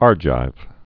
(ärjīv, -gīv)